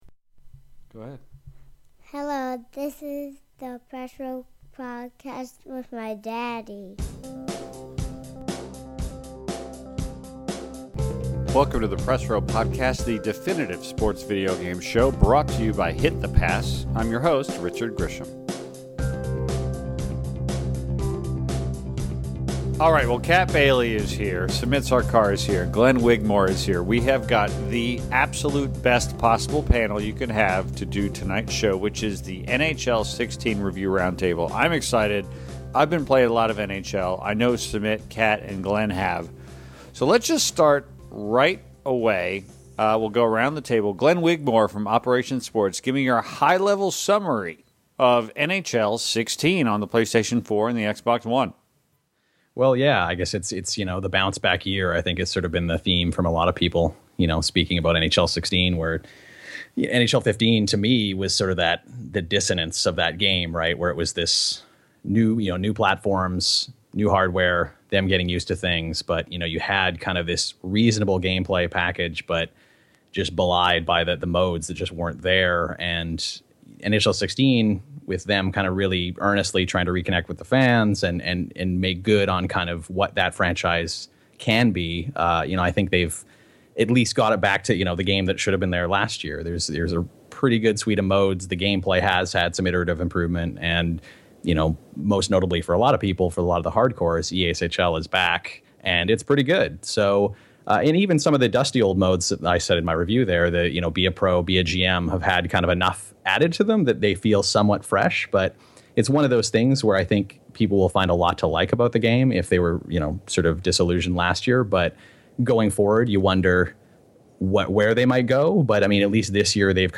NHL 16 Review Roundtable